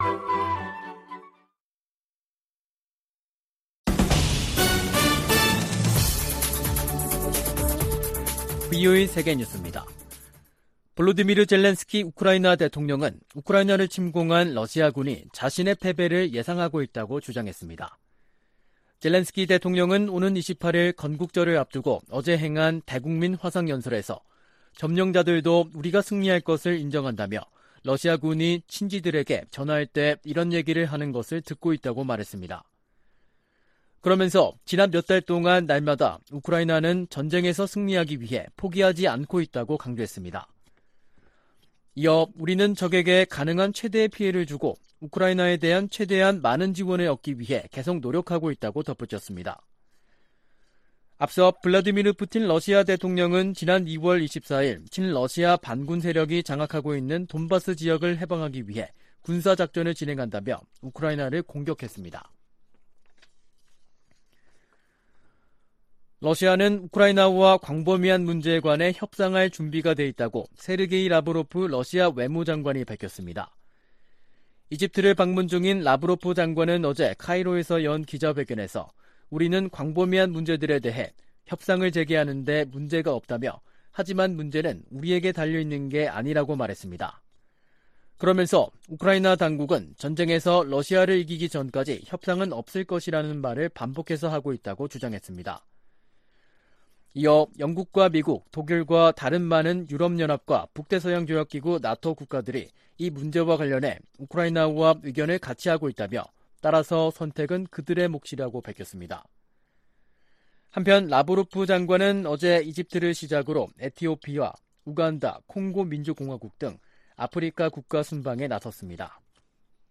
VOA 한국어 간판 뉴스 프로그램 '뉴스 투데이', 2022년 7월 25일 2부 방송입니다. 미 국무부의 빅토리아 눌런드 정무차관은 러시아와 북한의 밀착이 러시아의 고립을 보여주는 것이라고 평가했습니다. 일본과 아일랜드 정상이 북한의 탄도미사일 발사를 규탄하며 대량살상무기 완전 폐기를 촉구했습니다. 사이버 공격 대응을 위한 정부의 노력을 강화하도록 하는 ‘랜섬웨어 법안’이 미 하원 상임위원회를 통과했습니다.